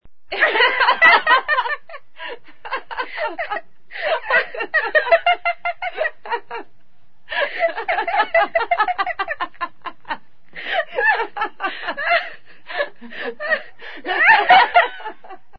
RISAS CHICAS RISAS DE MUJERES
EFECTO DE SONIDO DE AMBIENTE de RISAS CHICAS RISAS DE MUJERES
Risas_chicas_-_Risas_de_mujeres.mp3